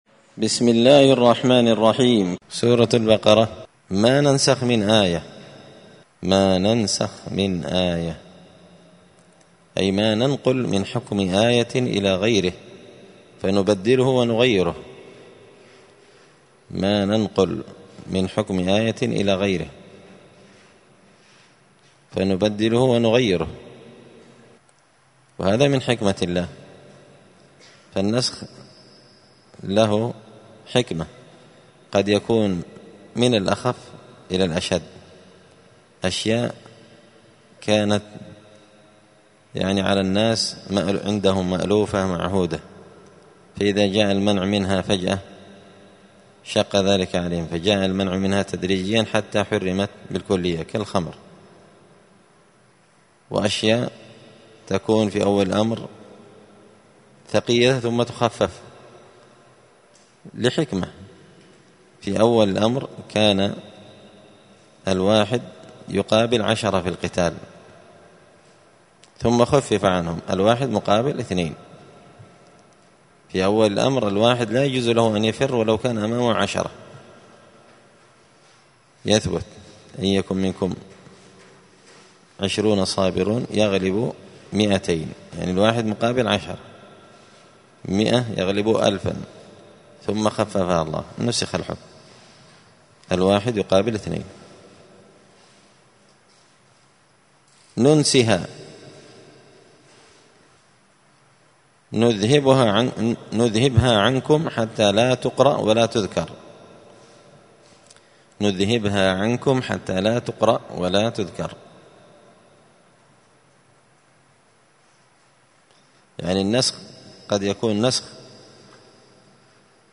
دار الحديث السلفية بمسجد الفرقان بقشن المهرة اليمن
*المجالس الرمضانية لفهم معاني السور القرآنية*